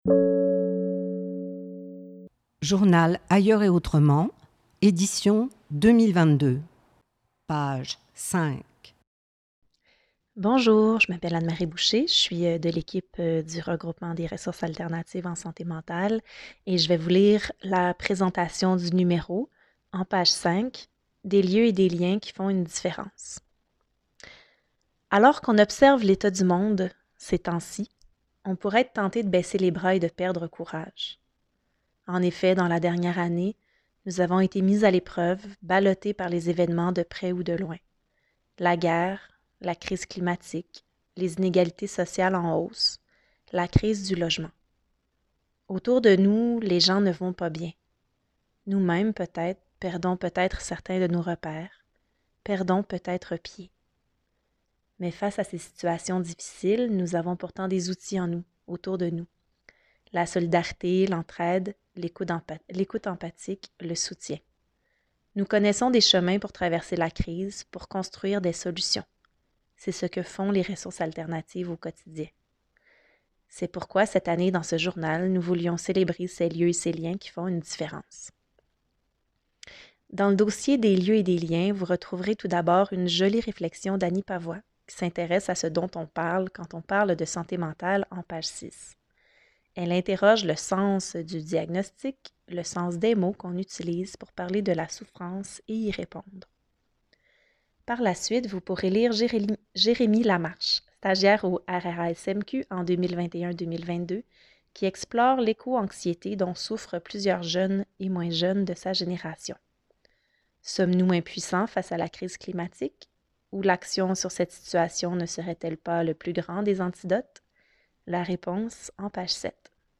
Nous avons souhaité refaire une version audio afin d’en faciliter le partage.